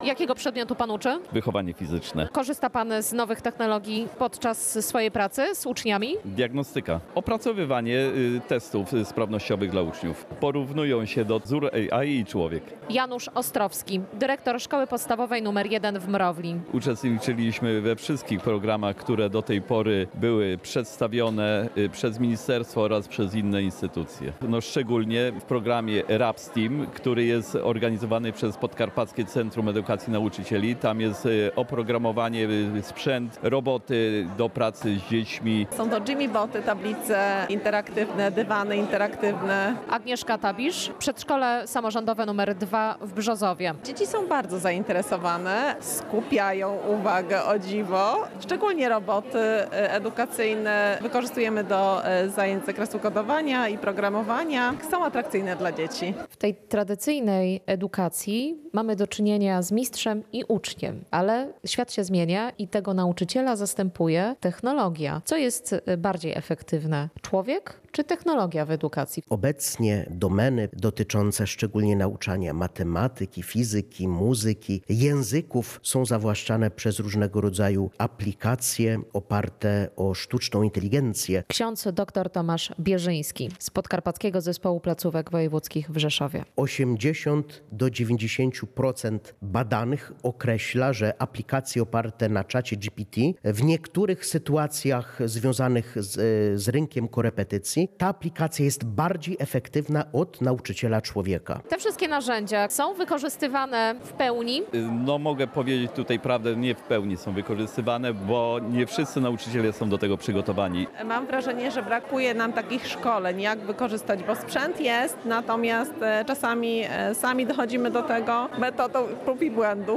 W Instytucie Teologiczno-Pastoralnym w Rzeszowie spotkali się dyrektorzy szkół, nauczyciele, terapeuci oraz przedstawiciele samorządów. Dyskusja dotyczyła praktycznego wdrażania transformacji cyfrowej w edukacji oraz wykorzystania nowoczesnych narzędzi w codziennej pracy z uczniami.